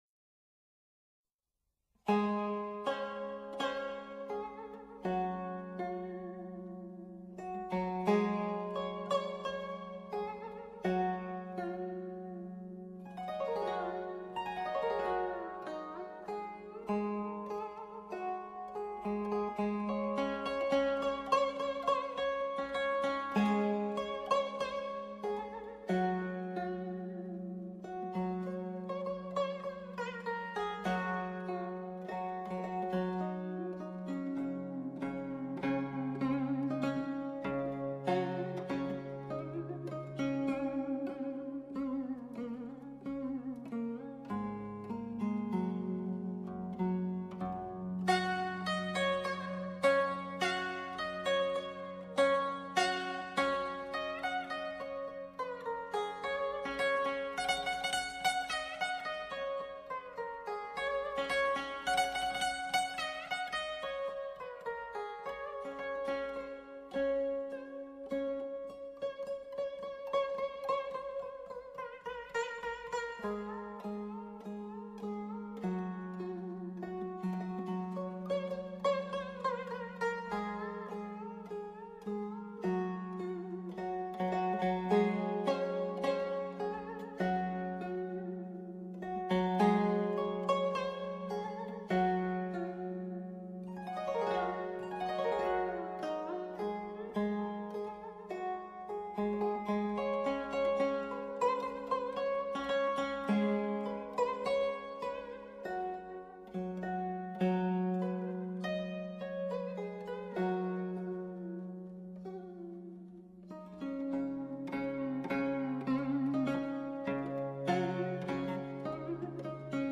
古筝1.mp3